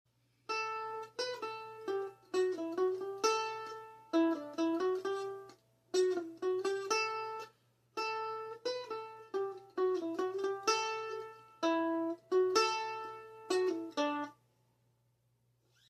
on the mandolin!